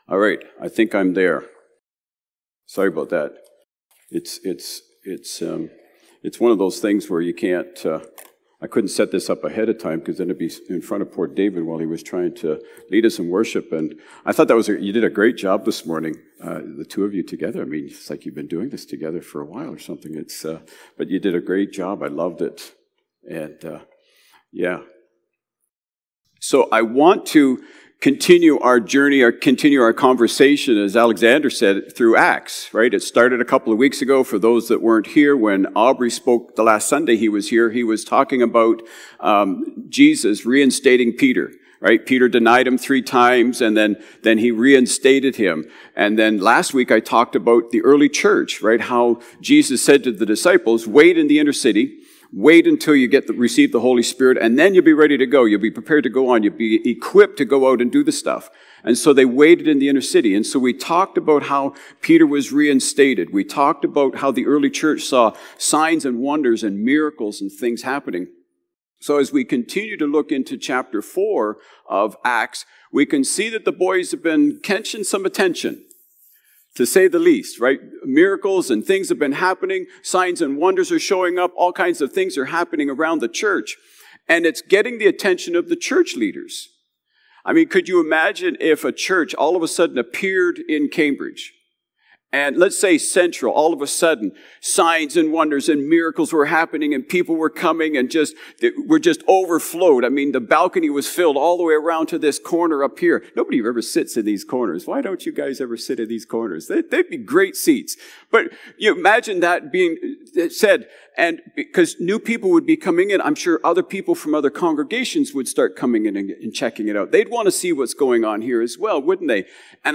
February-9-Sermon.mp3